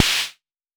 Modular Snare 02.wav